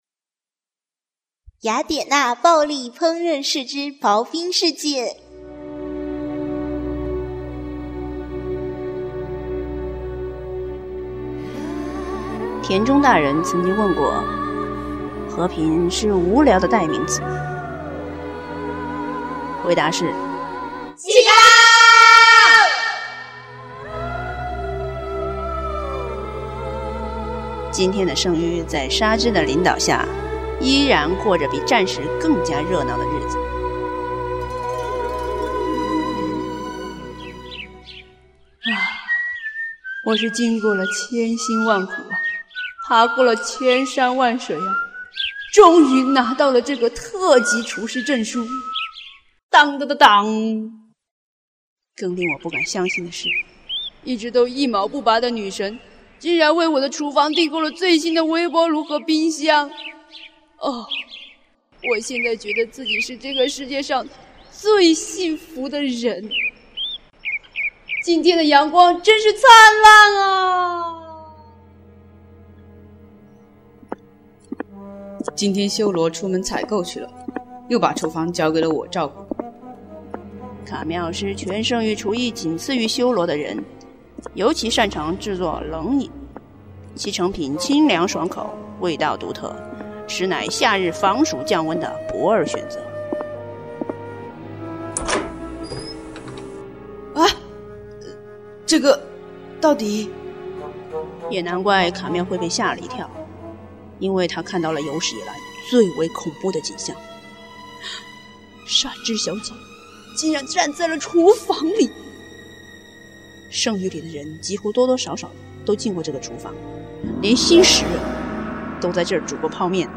圣同人恶搞Drama